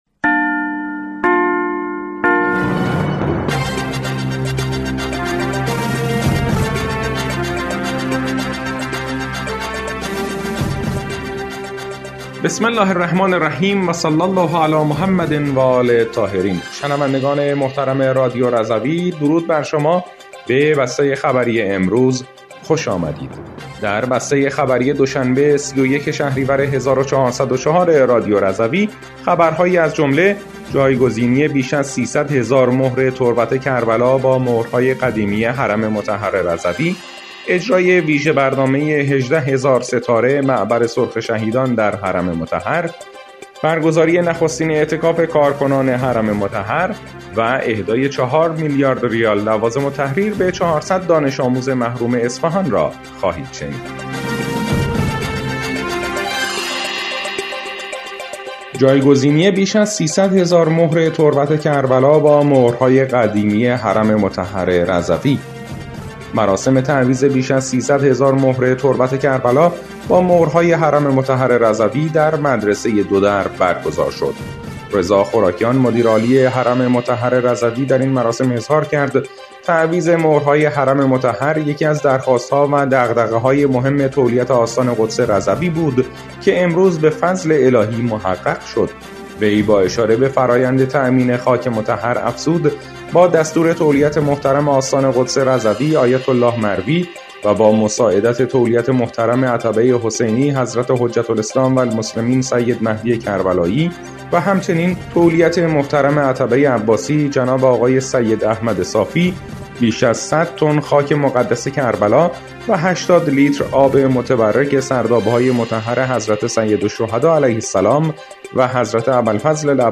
بسته خبری ۳۱ شهریور ۱۴۰۴ رادیو رضوی؛